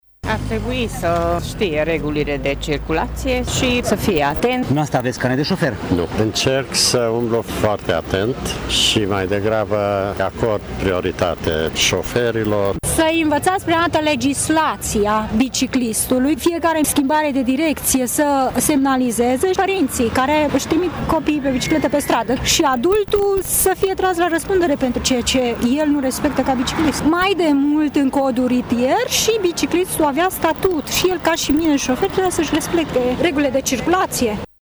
Unii dintre bicicliștii târgumureșeni nu au carnet de conducere auto și recunosc că au învățat regulile de circulație în timp ce se aflau pe șosele.